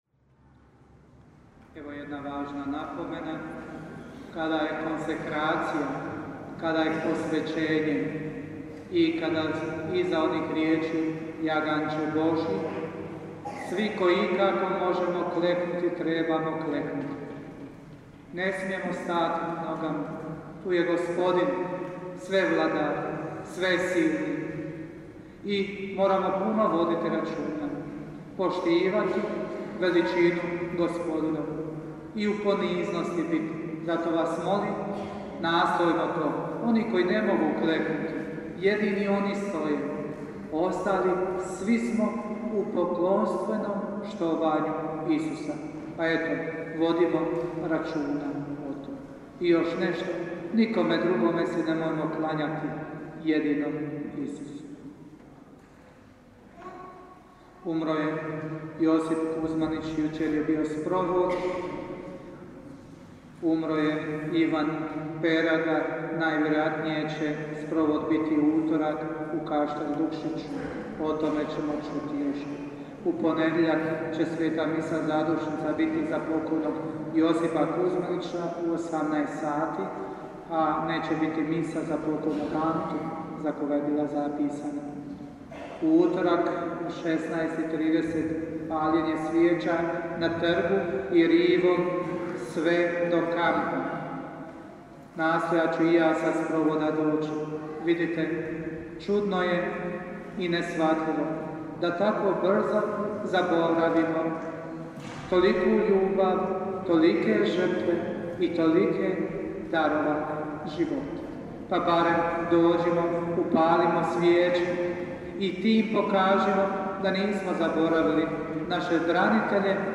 župne obavjesti (oglasi):